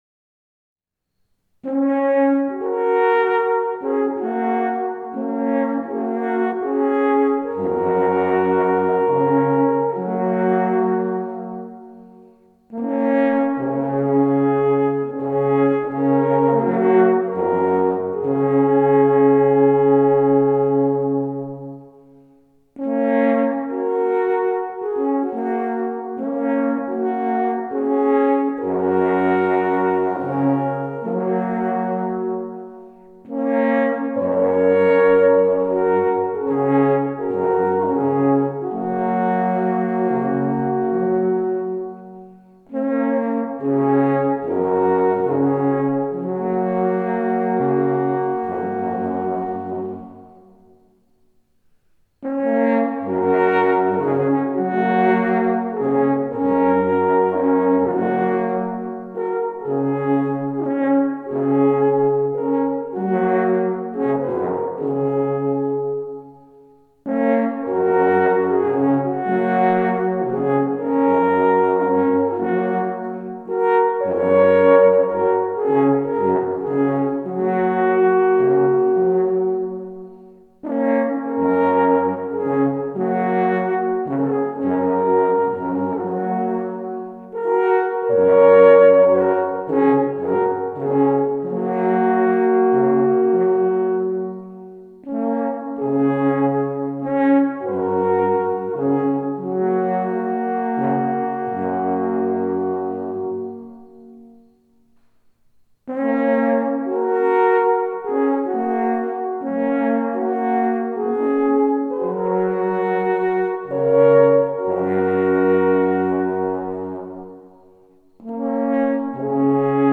Traditionelle und moderne Alphornmusik